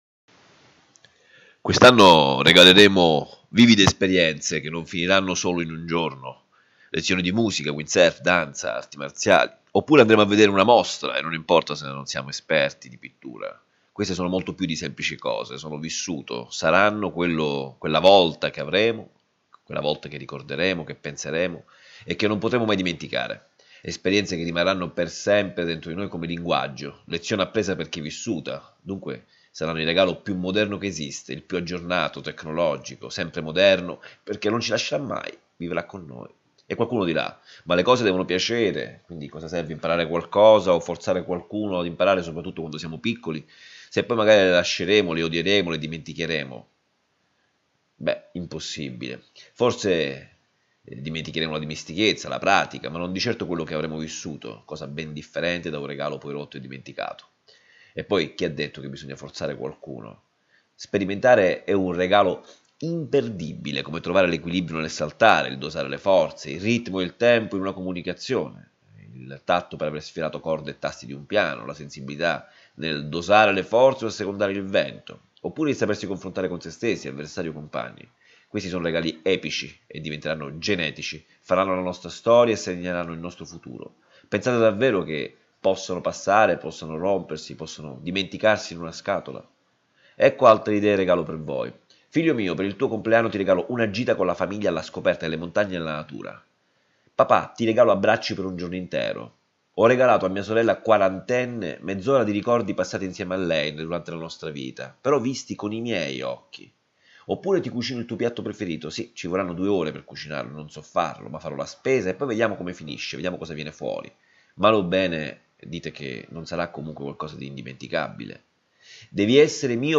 I minuti dei file audio riproducono le riflessioni degli articoli a voce alta, perché abbiano accesso all’ascolto i ciechi e quelli tra noi che pur avendo la vista sono diventati non vedenti,